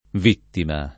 vittima [ v & ttima ] s. f.